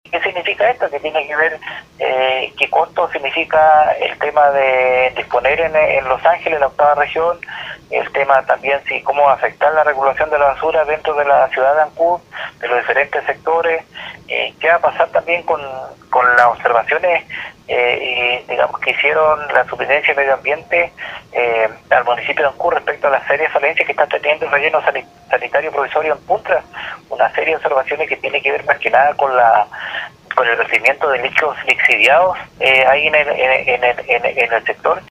08-CONCEJAL-ALEX-MUNOZ-2.mp3